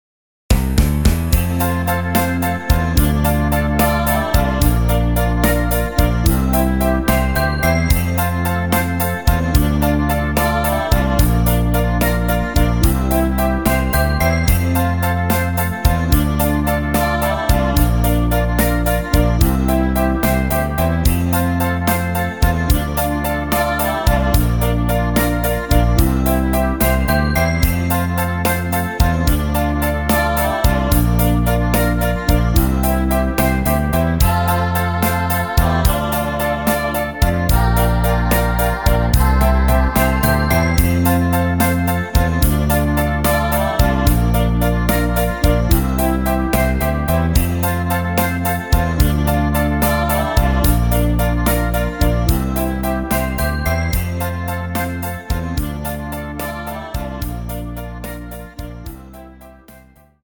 Rhythmus  Slowrock
Art  Englisch, Mega Hits, Oldies, Standard